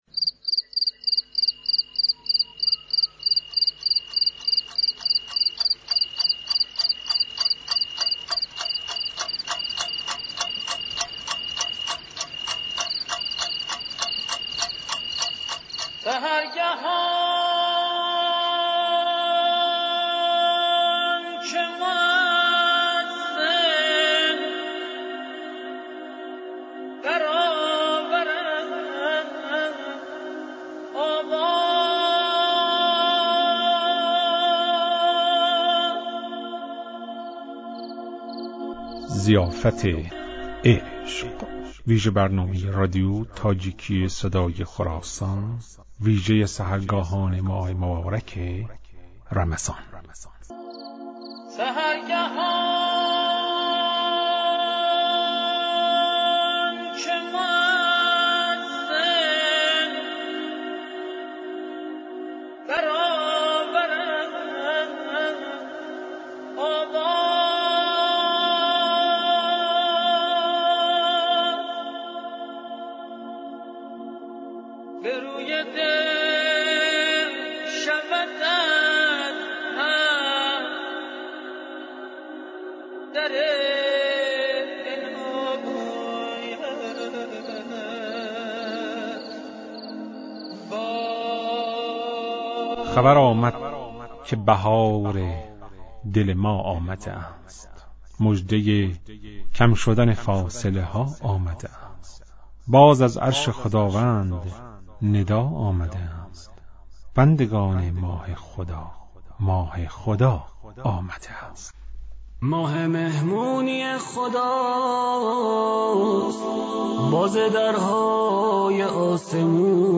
“Зиёфати ишқ” вижабарномае аст, ки ба муносибати айоми моҳи мубораки Рамазон дар радиои тоҷикӣ таҳия ва пахш мешавад.